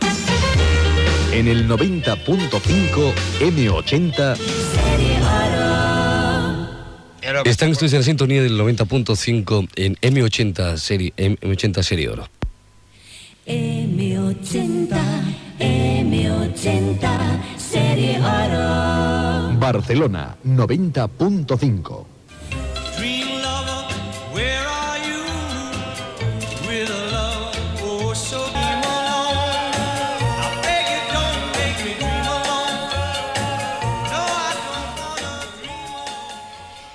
Identificació i indicatiu de l'emissora
FM